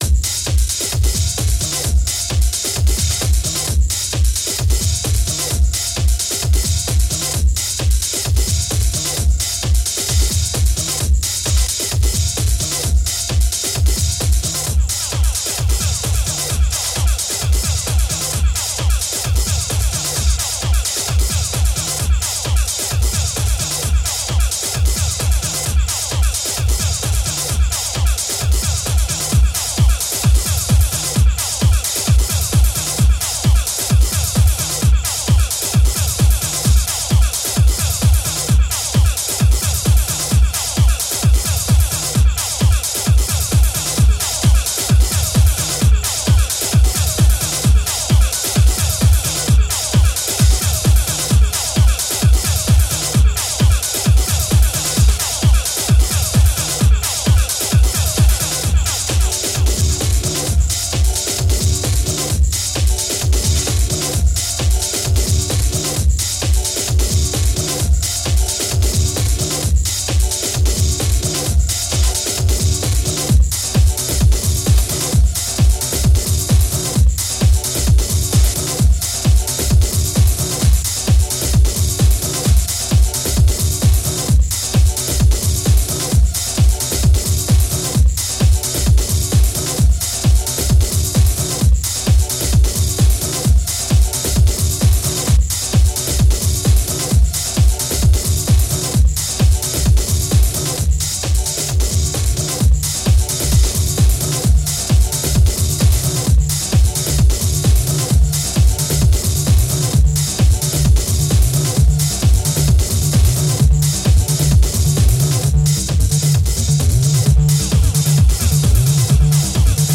Bangin' Acid Techno